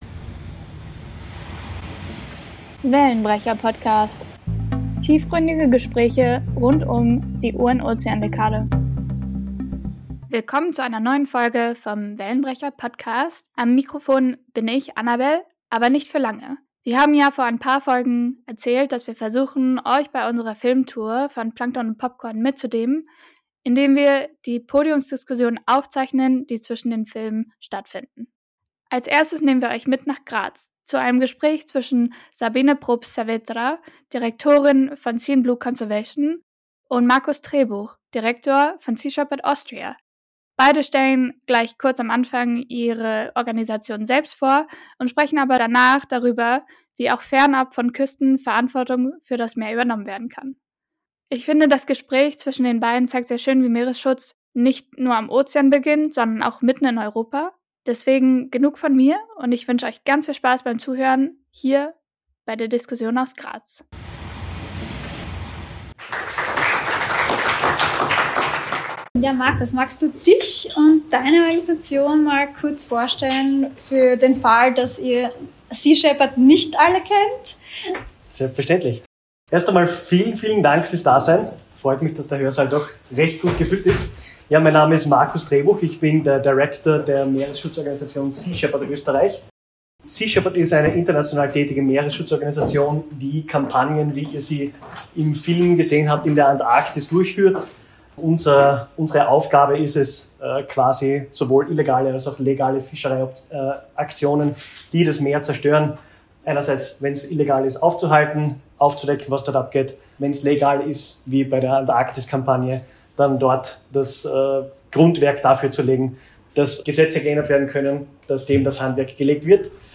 Die Podiumsdiskussion von Plankton & Popcorn aus Graz